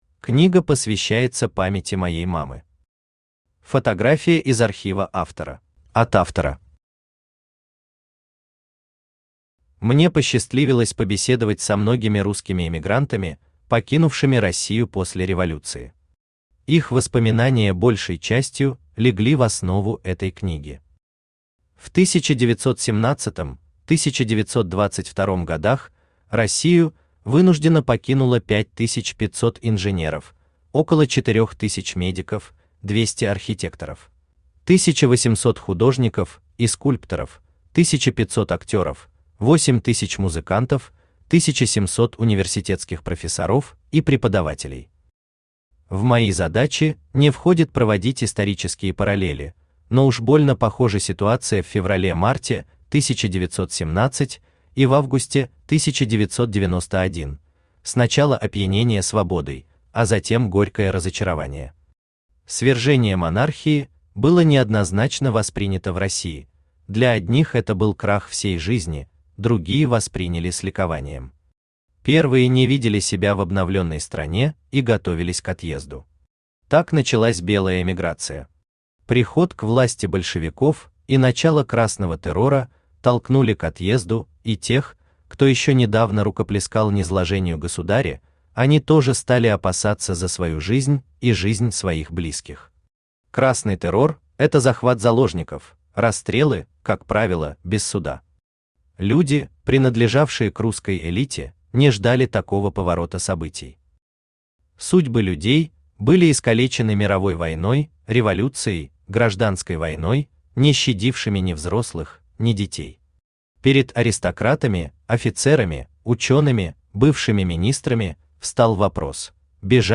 Аудиокнига Забытые в изгнании. Франция, Париж | Библиотека аудиокниг
Франция, Париж Автор Ростислав Смольский Читает аудиокнигу Авточтец ЛитРес.